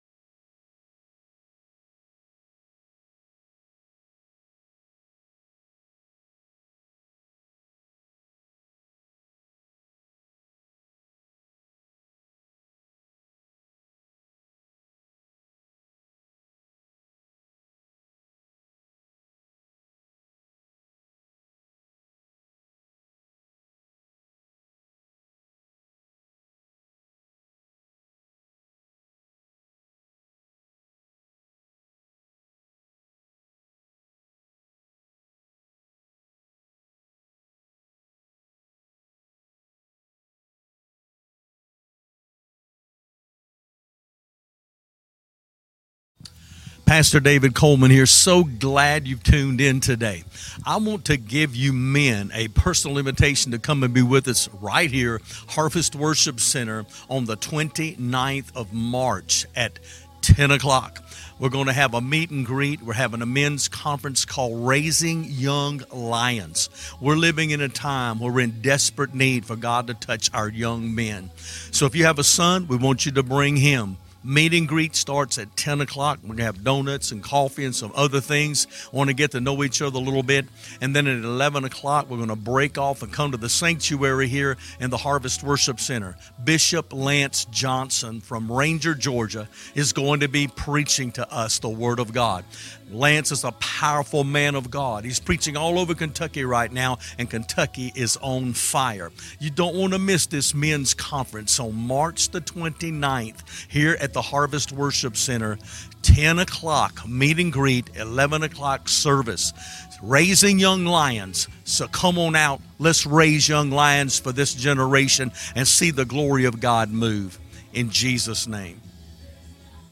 Sunday Morning Service.